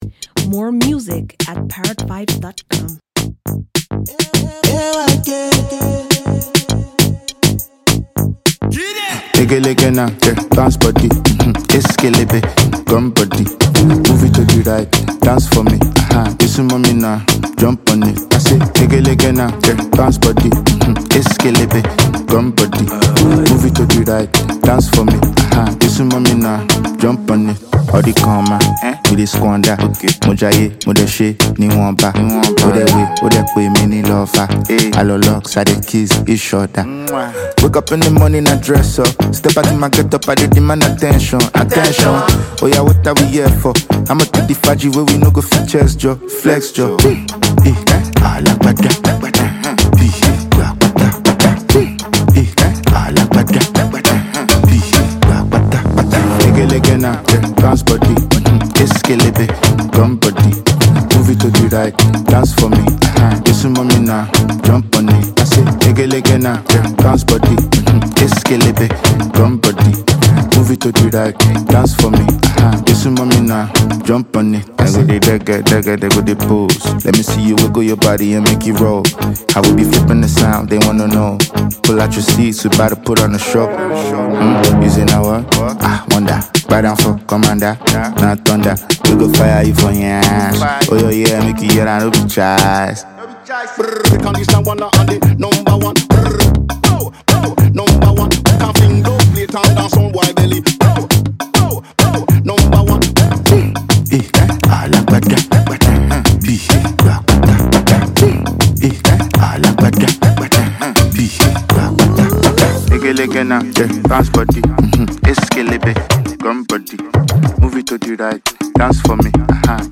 Illustrious Nigerian rap maestro
entrancing song